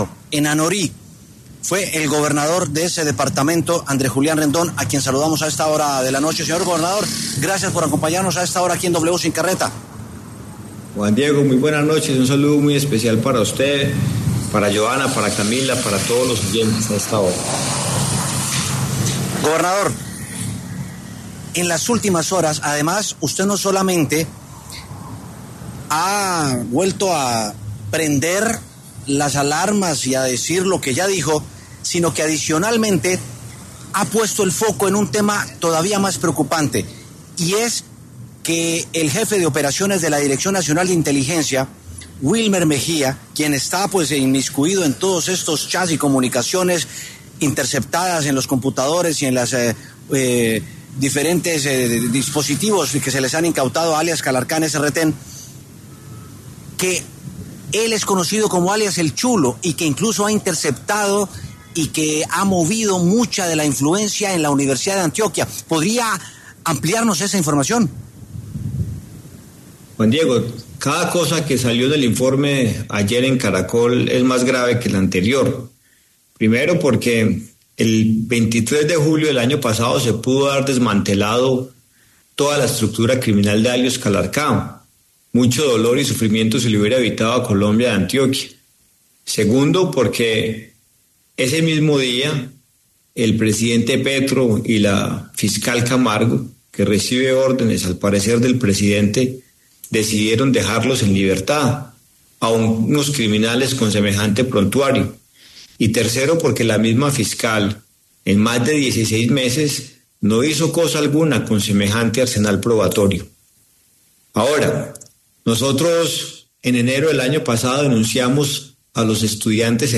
El gobernador de Antioquia Andrés Julián Rendón, pasó por los micrófonos de W Sin Carreta y habló de los presuntos nexos de las Farc con el Gobierno.